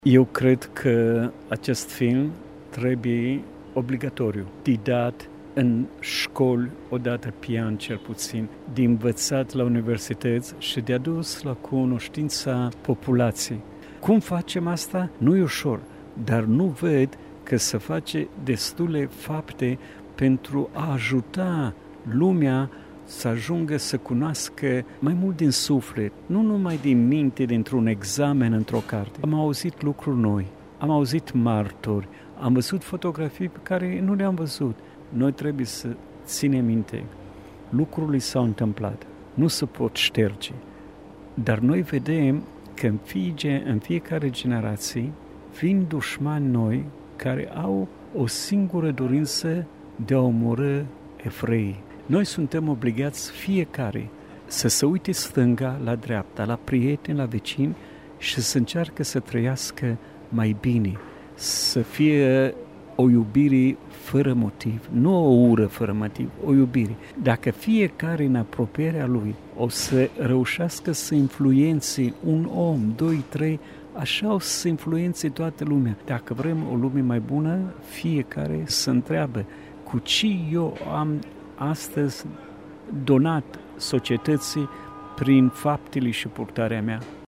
Curtea Casei Muzeelor a găzduit, miercuri, un ceremonial de comemorare a miilor de evrei uciși între 27 și 30 iunie 1941, în spațiul care atunci aparținea Chesturii Poliției și Jandarmeriei Române.